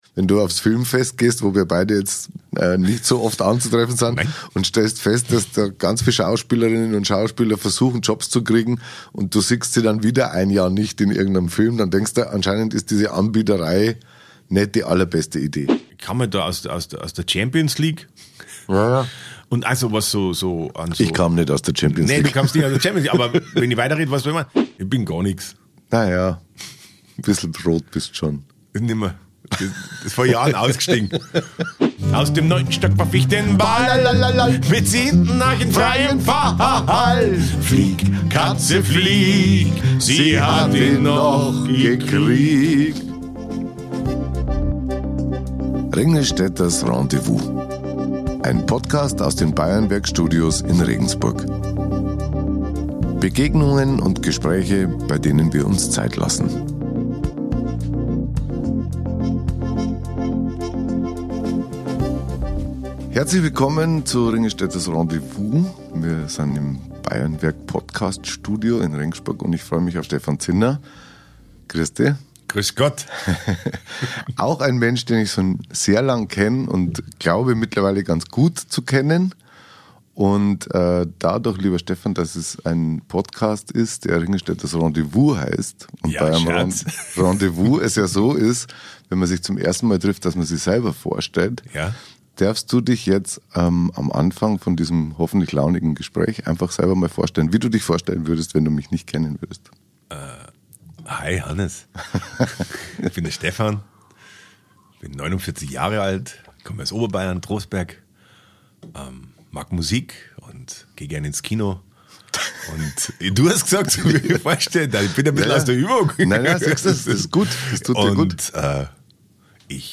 Dieses Mal habe ich den unvergleichlichen Stefan Zinner zu Gast. Er erzählt wie die Schauspielerei ihm als junger Mann mit den Mädels geholfen hat, warum er die Musik so schätzt, wie er dem Thema Glauben gegenübersteht und so manches mehr. Und ein bissl Musik gibt’s dann auch noch.